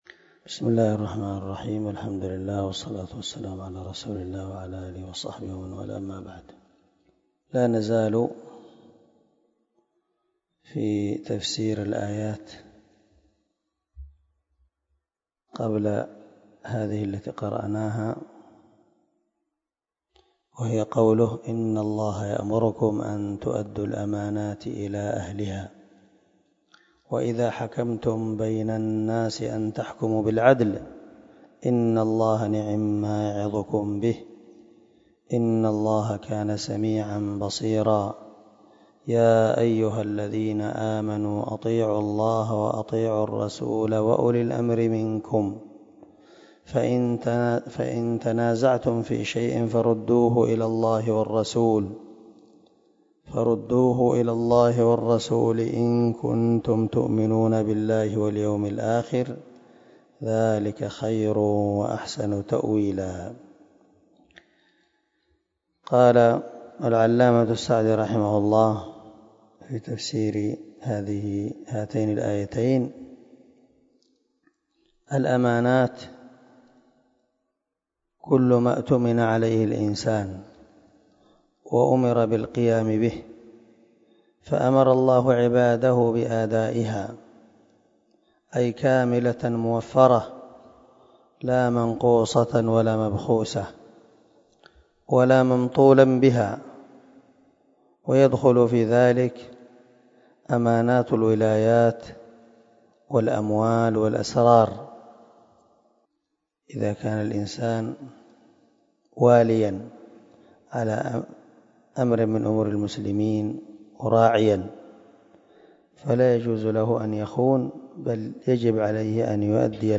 274الدرس 42 تابع تفسير آية ( 58 – 59 ) من سورة النساء من تفسير القران الكريم مع قراءة لتفسير السعدي
دار الحديث- المَحاوِلة- الصبيحة.